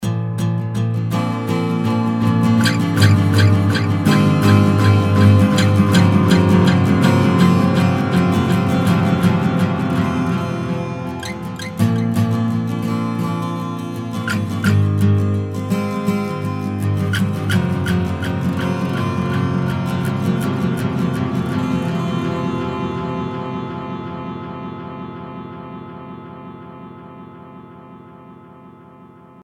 Classic rackmount digital monophonic delay unit with modulation.
demo guitar delay : too much feedback :)